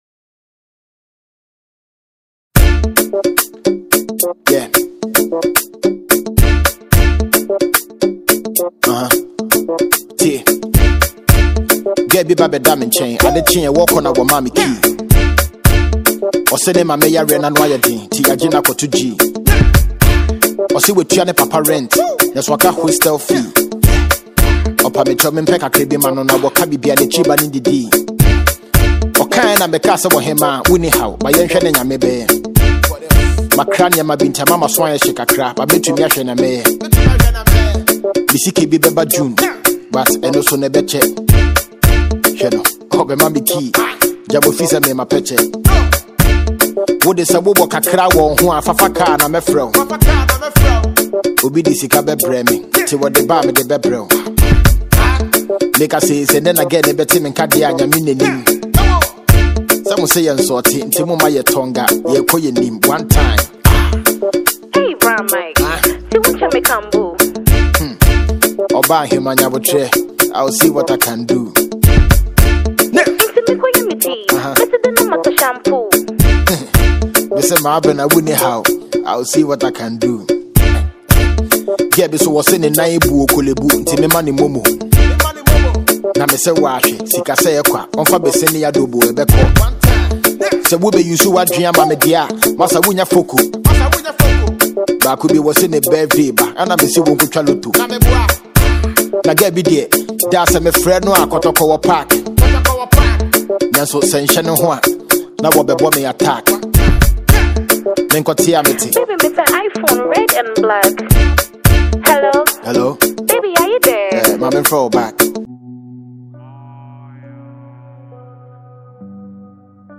Freestyle Rap